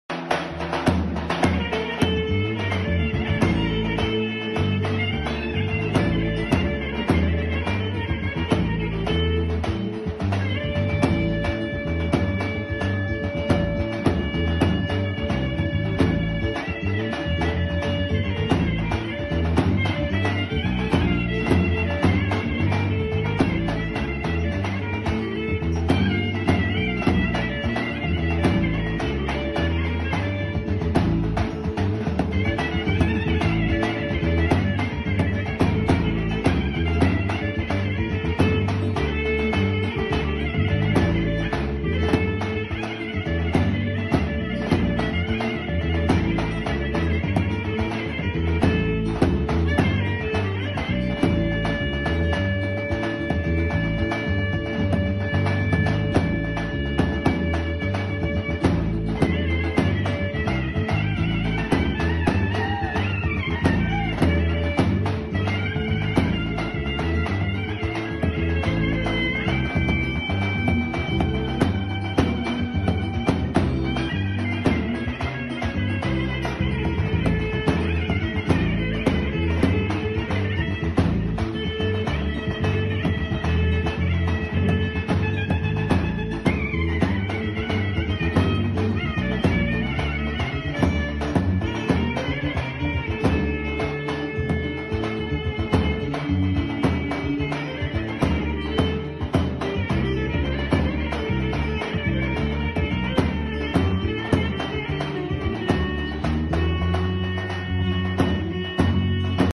Klarnet